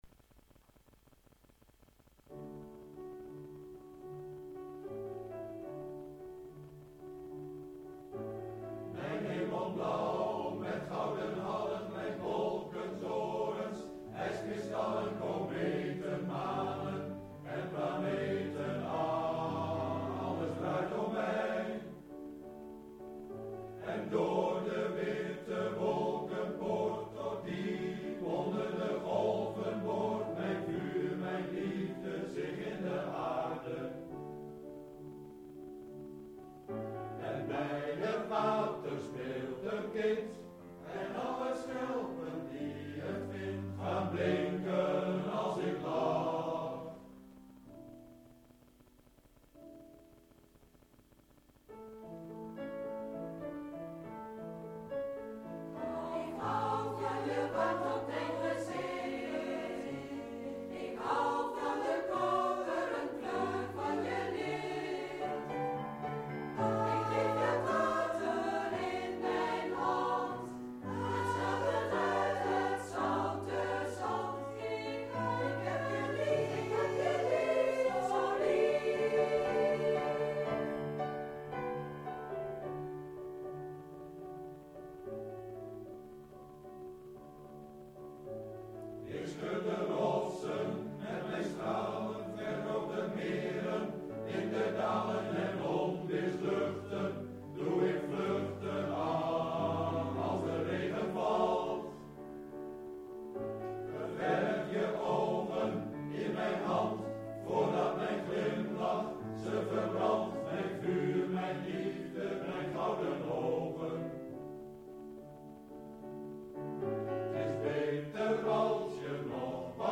De (technische) kwaliteit loopt nogal uiteen.